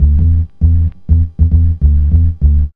2708L B-LOOP.wav